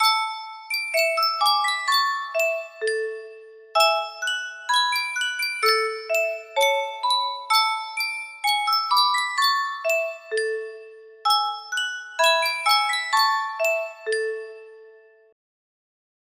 Sankyo Music Box - Silver Threads Among the Gold NGH music box melody
Full range 60